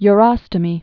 (y-rŏstə-mē)